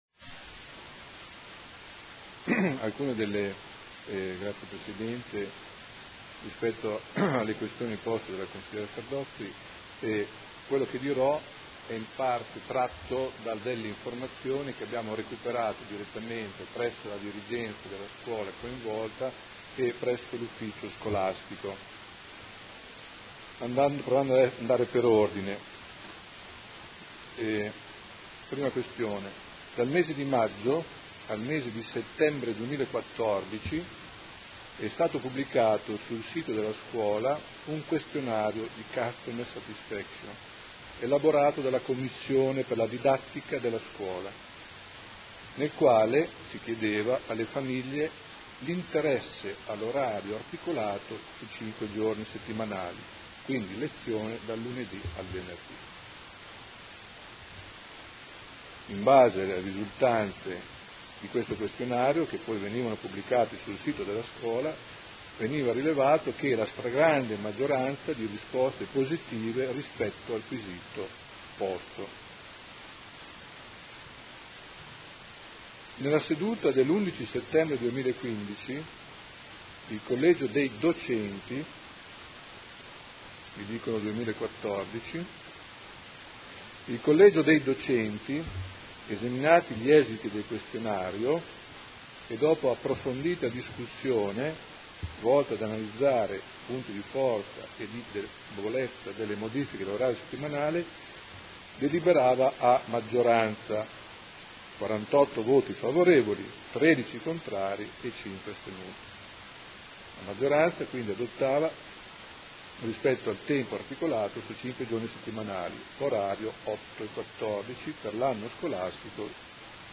Seduta del 5/11/2015. Interrogazione del Gruppo Consiliare Movimento 5 stelle avente per oggetto: Orario anno scolastico 2015/16 per l’Istituto Secondario Statale di 1° grado “Pasquale Paoli” (Sede Paoli – Sede San Carlo).